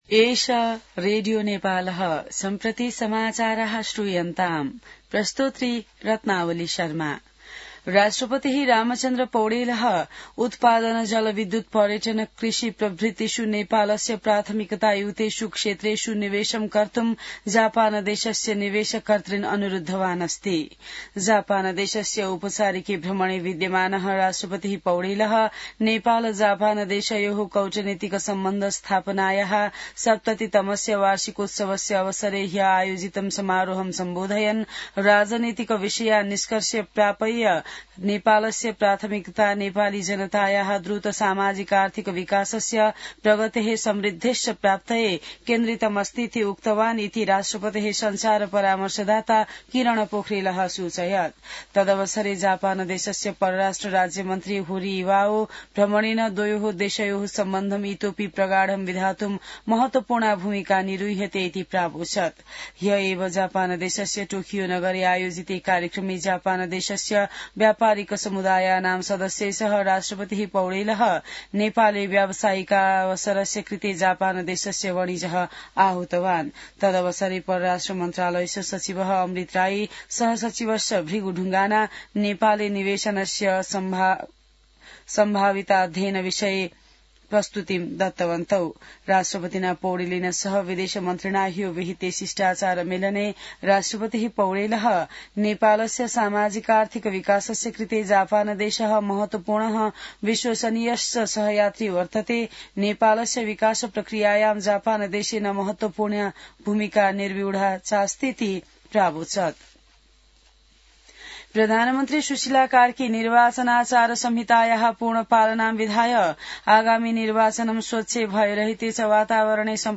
संस्कृत समाचार : २० माघ , २०८२